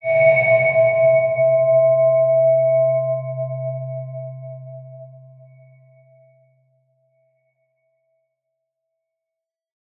X_BasicBells-C1-mf.wav